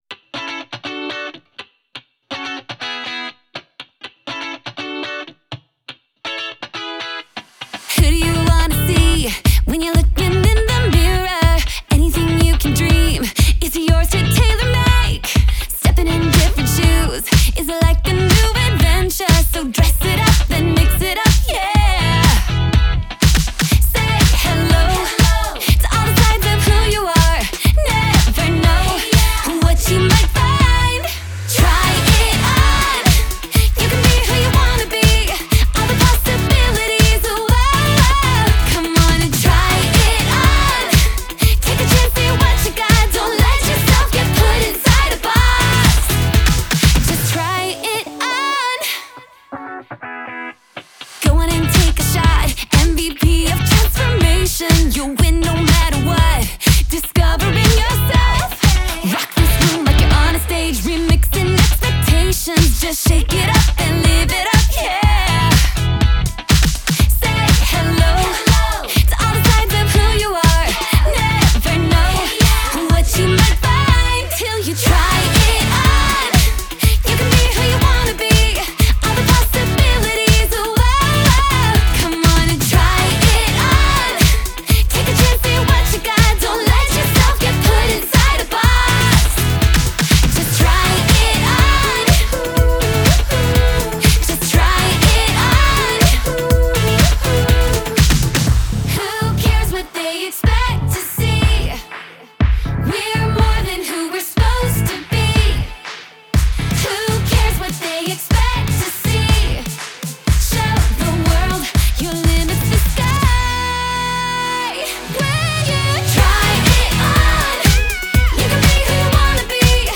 Жанр: Pop music
Genre - Pop, Aqua-core